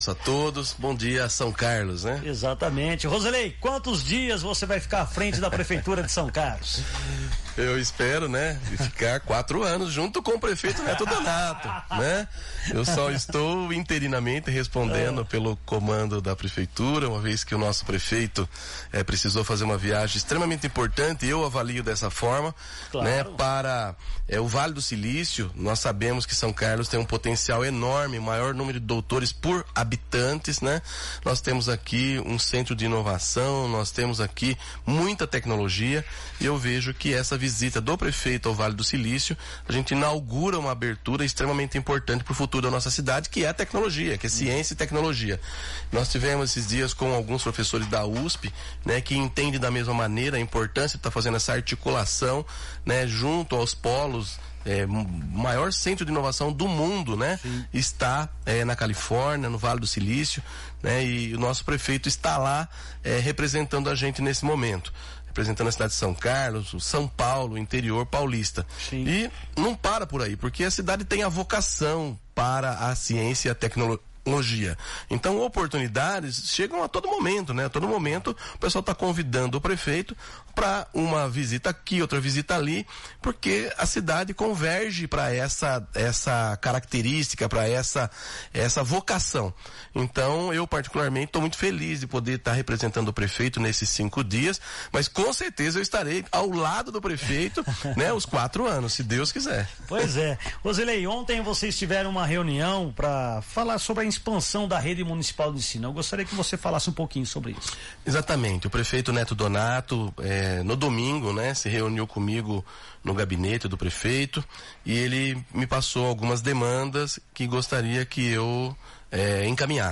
Prefeito interino, Roselei Françoso, participa de entrevista na São Carlos FM |
Enquanto o prefeito Netto Donato (PP) cumpre agenda no Vale do Silício, na Califórnia, para aproximar São Carlos dos principais polos de inovação tecnológica do mundo, o município é comandado interinamente pelo vice-prefeito, Roselei Françoso, que participou na manhã desta terça-feira (26), de uma entrevista ao programa Primeira Página no Ar, da São Carlos FM (107,9).